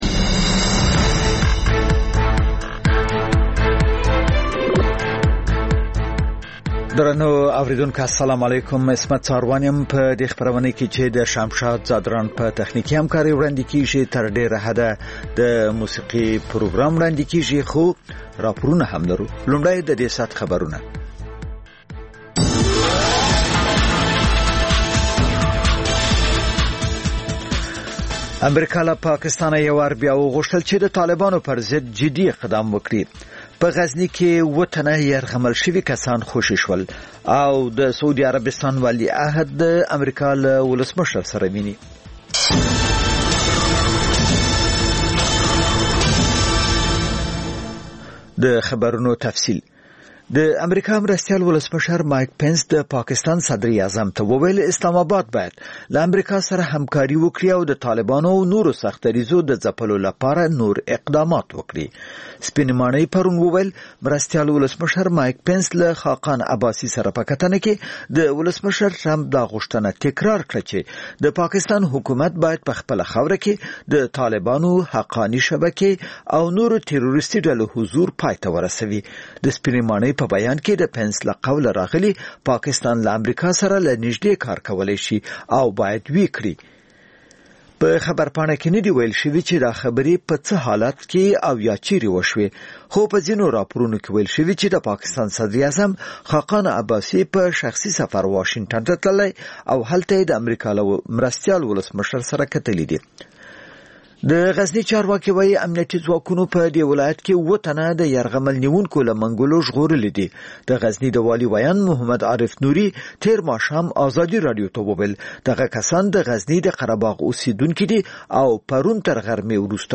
خبرونه، راپورونه او د سندرو مېلمانه